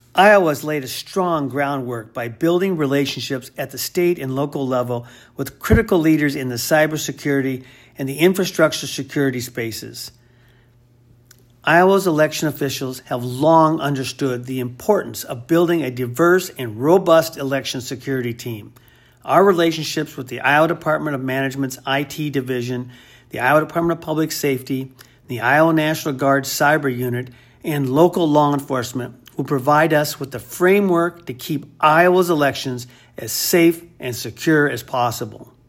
A soundbite from Secretary Pate is available for your use here.